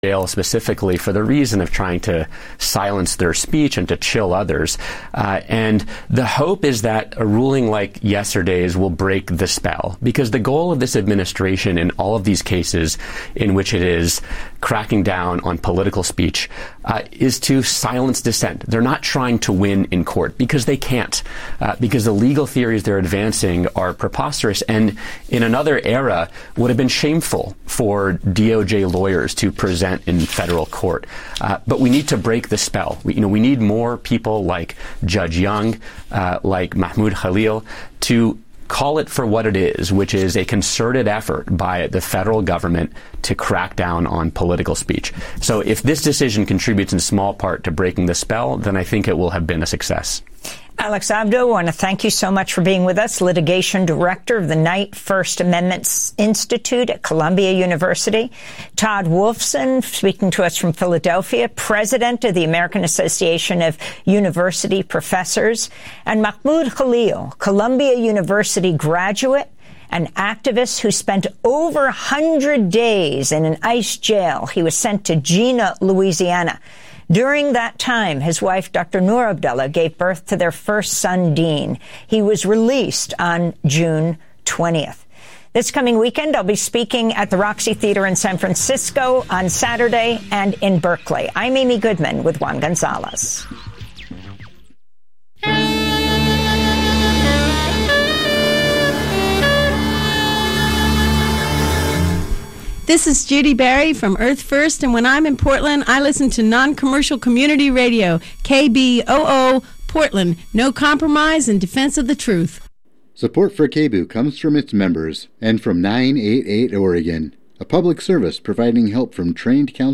Evening News on 10/01/25